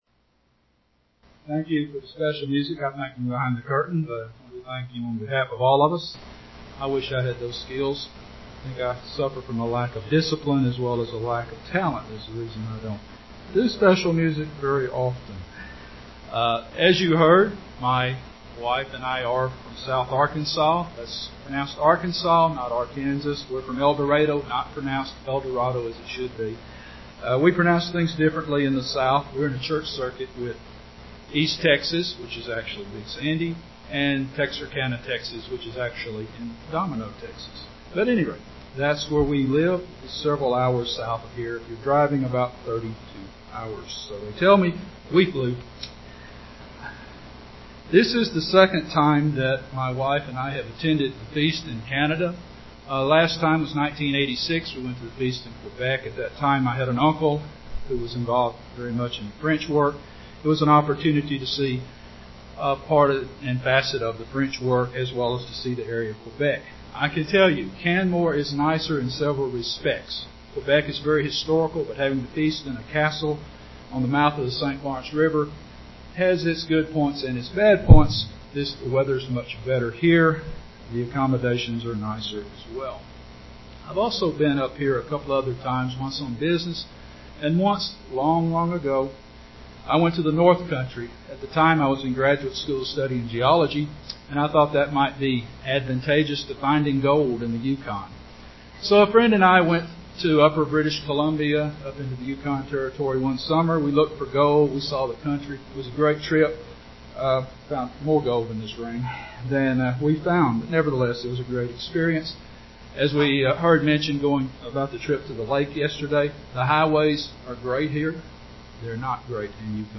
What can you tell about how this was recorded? This sermon was given at the Canmore, Alberta 2015 Feast site.